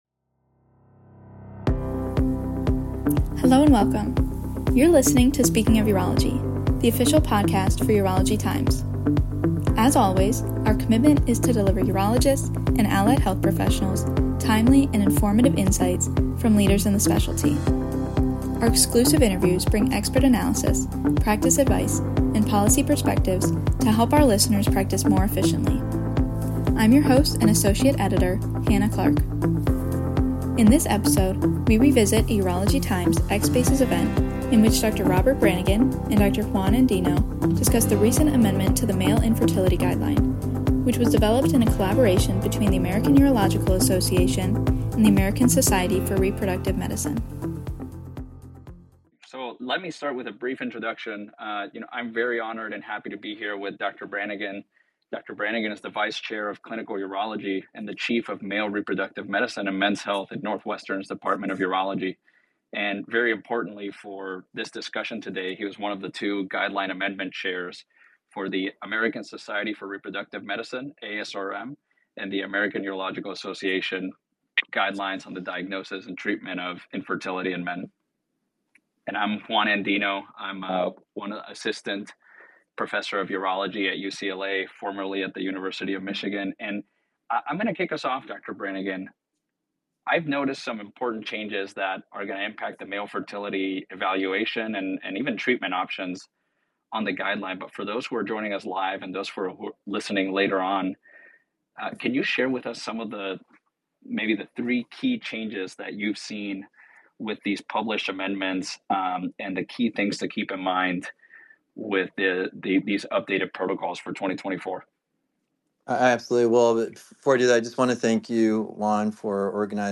This discussion originally took place during a live X (formerly Twitter) Spaces event held by Urology Times®.